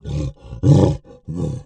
MONSTERS_CREATURES
MONSTER_Effort_07_mono.wav